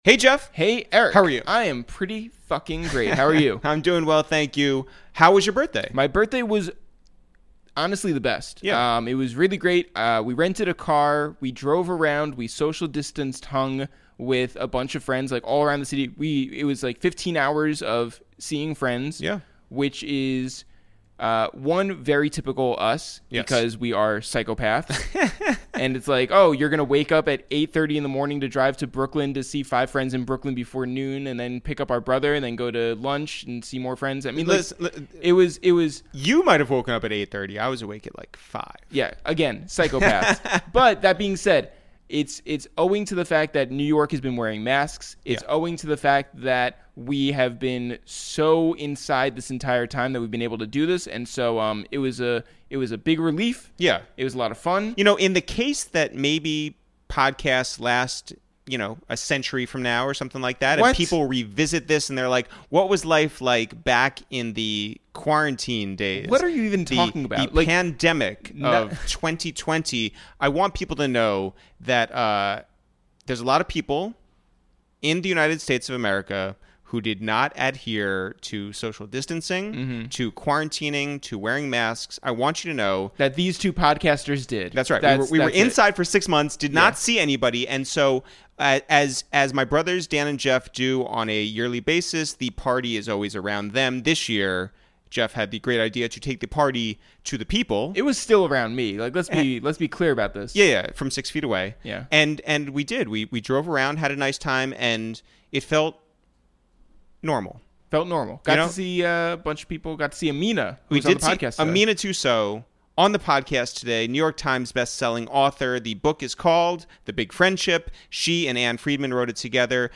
With Amina on the phone, we discuss the expectations when signing a book contract, the relationship between authors and the publisher's editor, the benefits of bringing on an additional editor, who exactly Ann and Amina wrote their memoir for, what they learned about friendship overall and specifically in a quarantine, and the feelings that come with presenting art to the public. We talk about Trump's disastrous response to COVID-19, his disastrous presidency and his meaningless game show Celebrity Apprentice, the pod family Amina has lived with over the last six months, the must-watch elements of Tik Tok, how NYC has changed during this crisis and how disappointing Bill De Blasio has been as mayor.